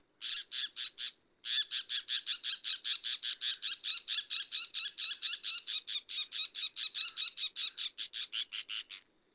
ヘルプ 詳細情報 モズ 大きさ スズメくらい 季節 春夏秋冬 色 茶 特徴 全長20cm。頭部が大きく、尾が長い褐色の鳥。